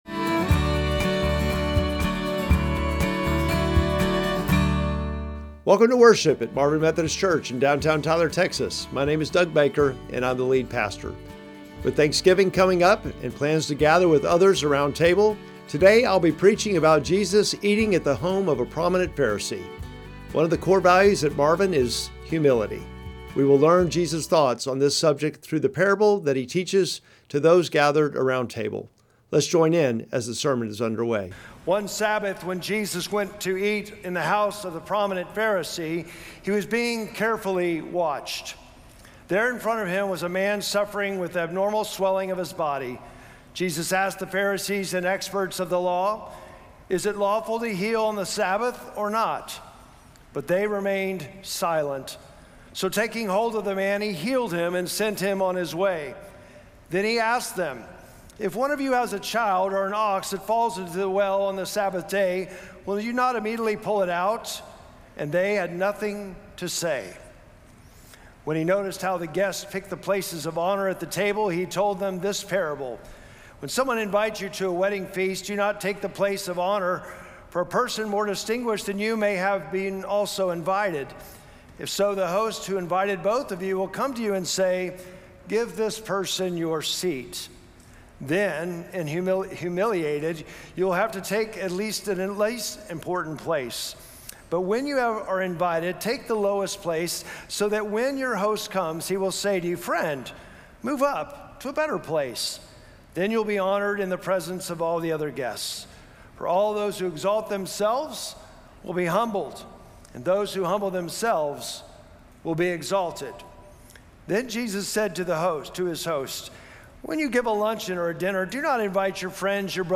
Sermon text: Luke 14:1-14